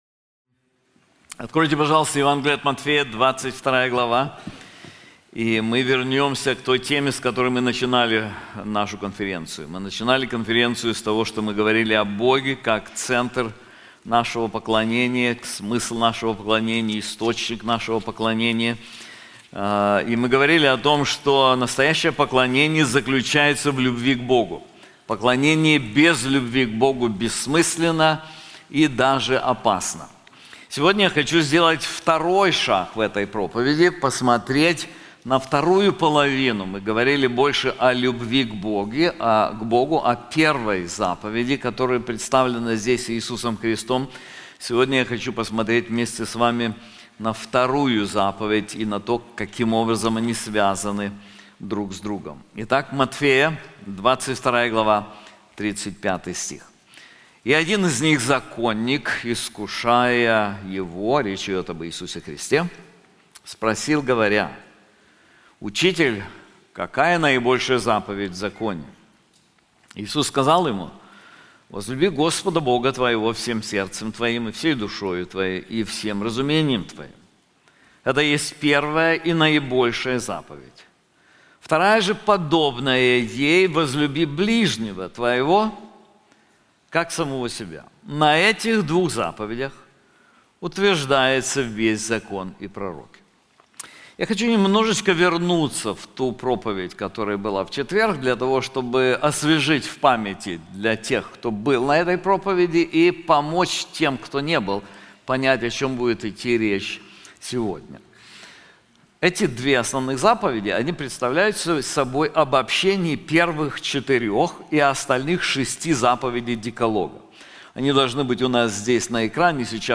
Конференции